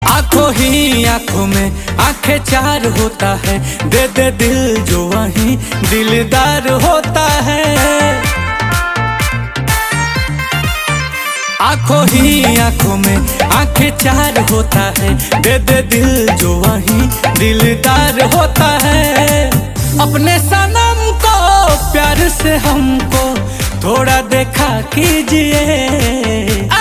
Bhojpuri Songs